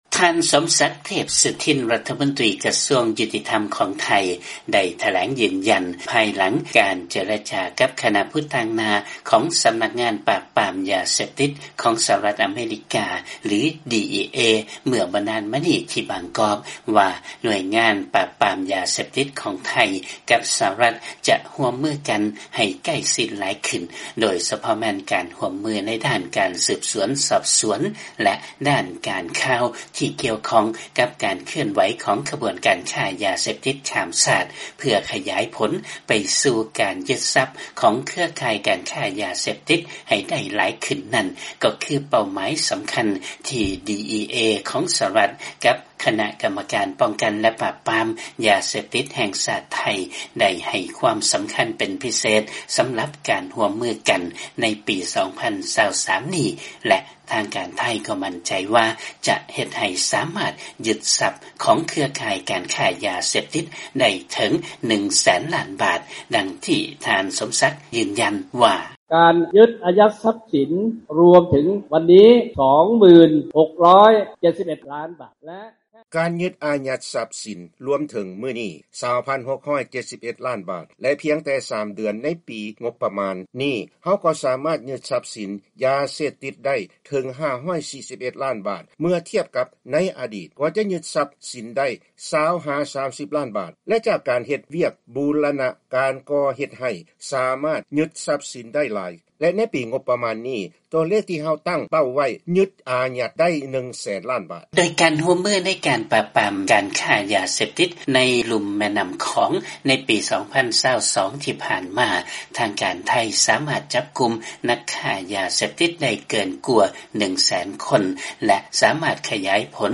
ຟັງລາຍງານ ລັດຖະມົນຕີໄທວາງເປົ້າໝາຍທີ່ຈະຍຶດຊັບຂອງນັກຄ້າຢາເສບຕິດໃຫ້ໄດ້ເຖິງ 100,000 ລ້ານບາດໃນປີ 2023